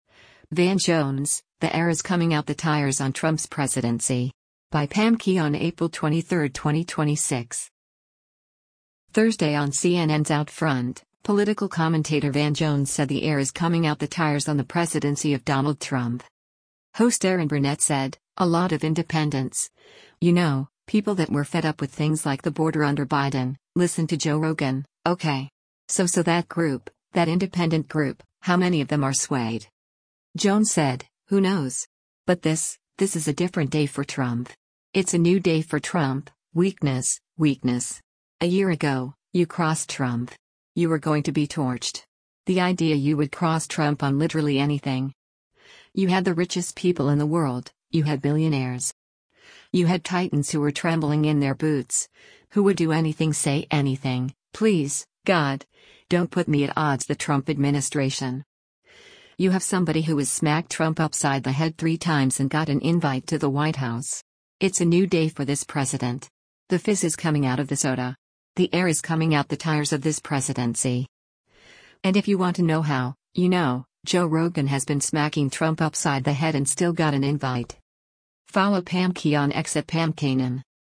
Thursday on CNN’s “OutFront,” political commentator Van Jones said “the air is coming out the tires” on the presidency of Donald Trump.